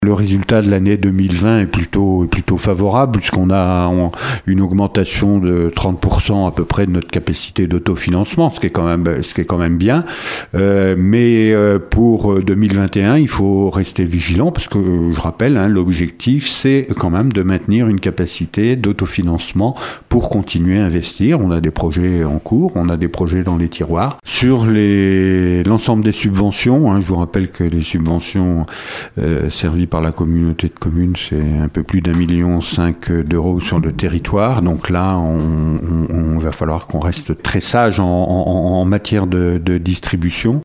Mais il va falloir aussi piocher ailleurs, comme le précise le président Jean Gorioux :